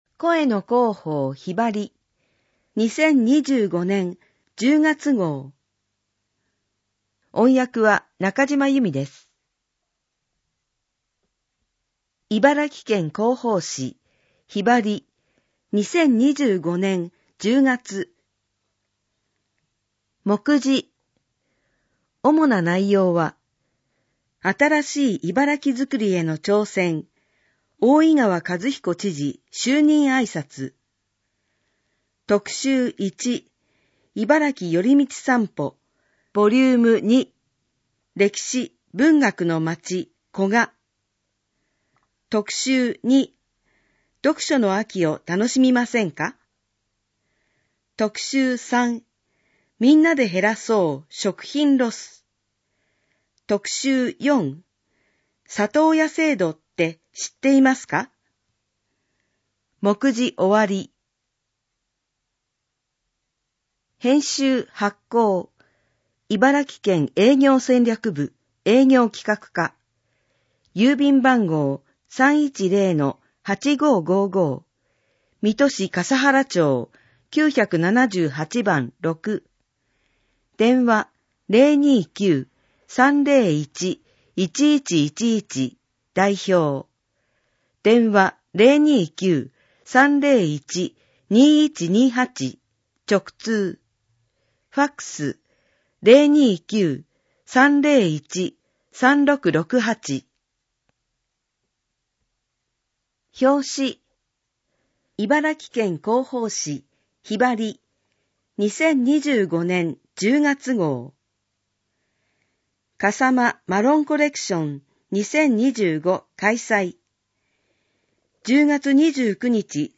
声の広報「ひばり」 音声を再生するためには、 「QuicktimePlayer」（外部サイトへリンク） 、 「WindowsMediaPlayer」（外部サイトへリンク） 、 「RealPlayer」（外部サイトへリンク） （いずれも無料）などが必要です。